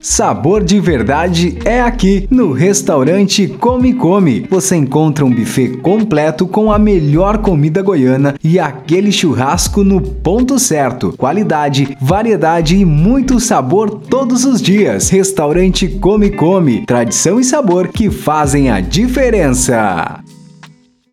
Padrão/Neutra: